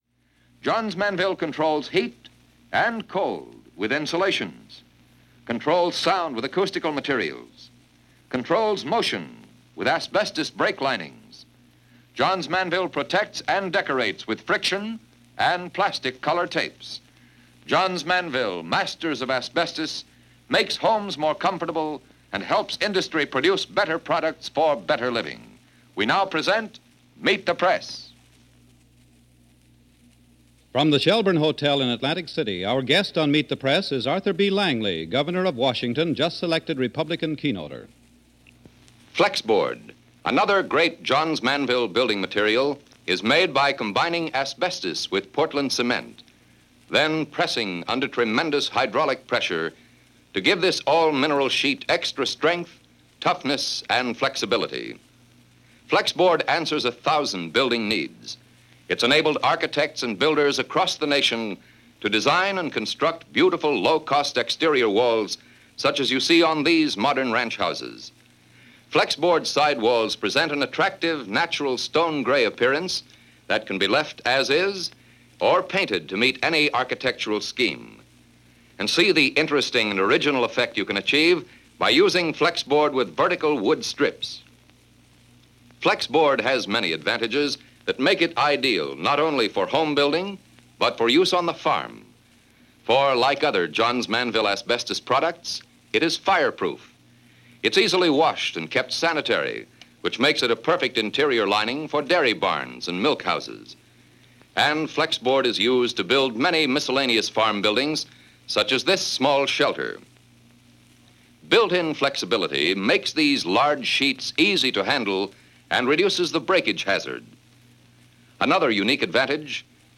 Washington State Governor and Keynote Speaker for the 1956 GOP Convention is interviewed on Meet The Press - June 26, 1956.